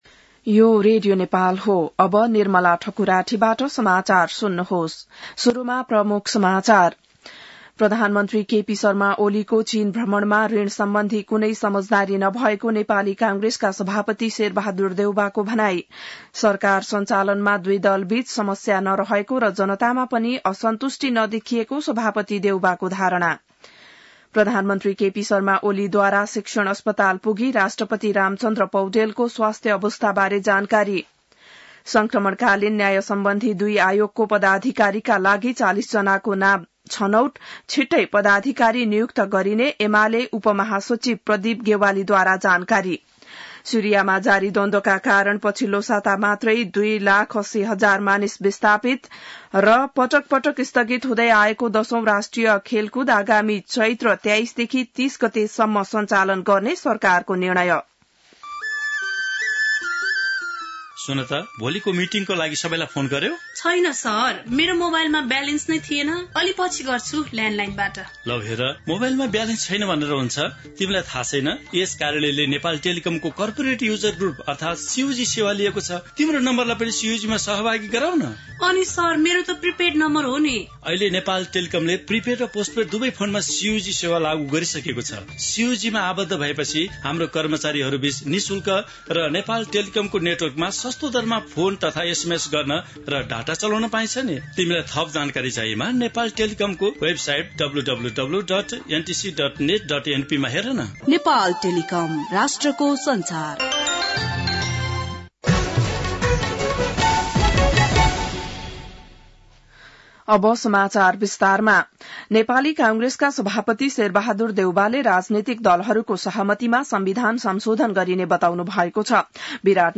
बिहान ७ बजेको नेपाली समाचार : २३ मंसिर , २०८१